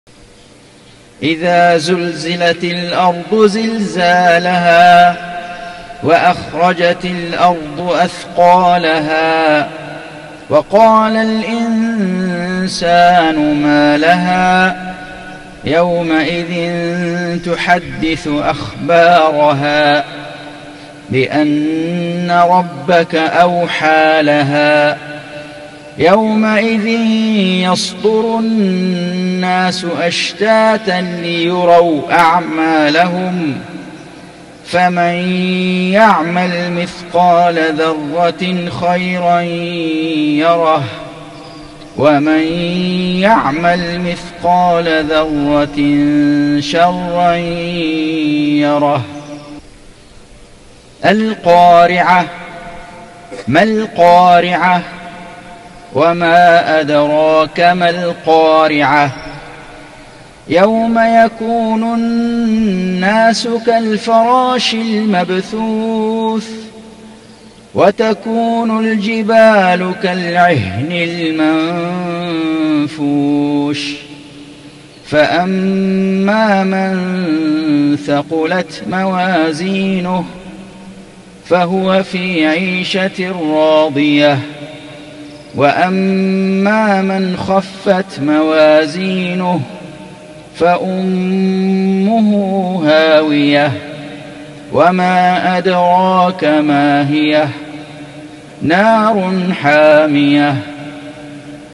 صلاة المغرب 4-2-1440 سورتي الزلزلة والقارعة || Maghrib prayer from Surah Az-Zalzala and Al-Qaari'a > 1440 🕋 > الفروض - تلاوات الحرمين